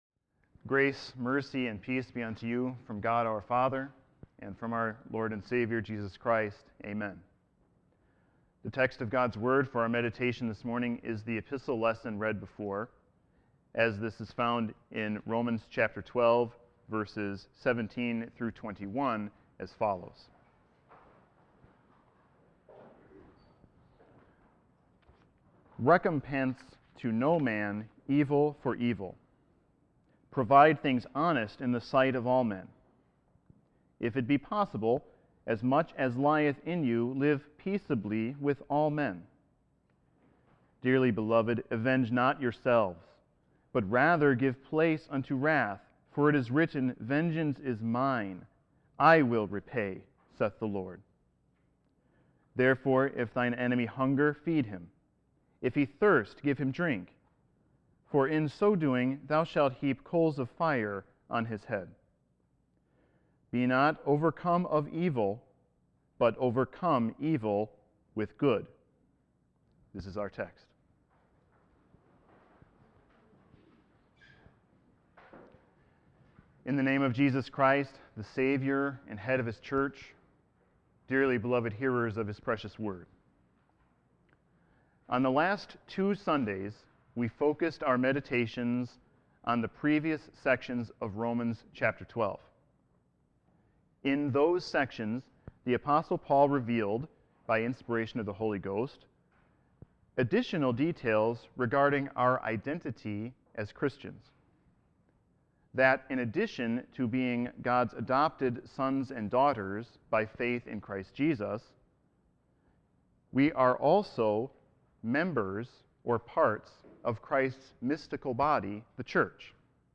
Sermon 1-26-14.mp3